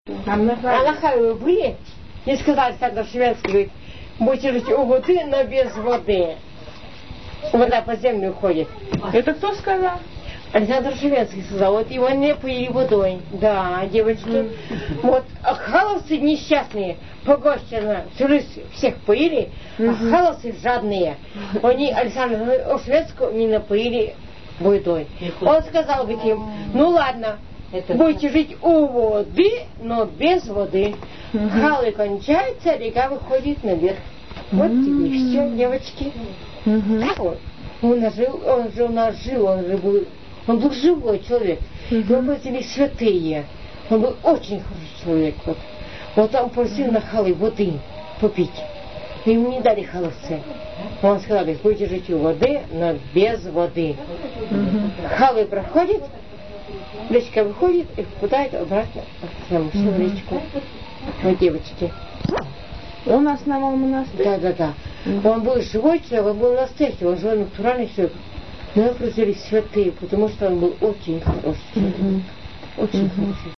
01 Легенда об Александре Ошевенском и реке, ушедшей под землю